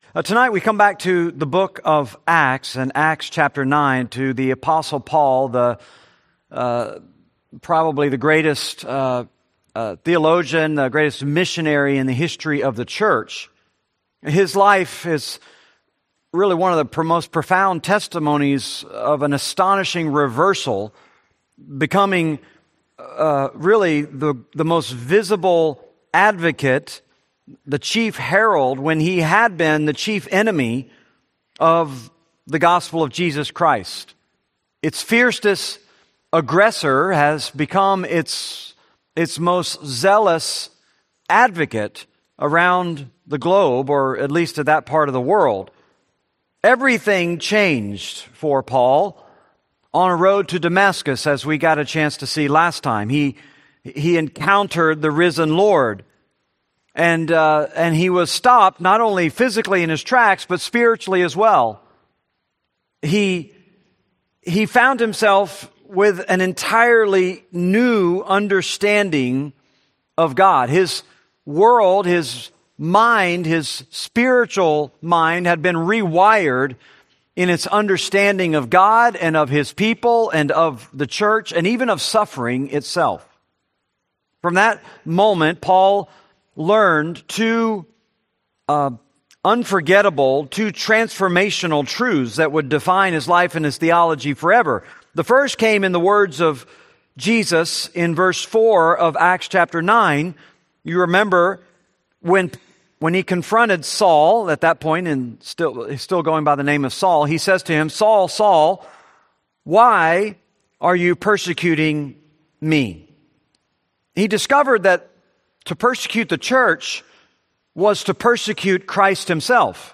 Series: Benediction Evening Service, Bible Studies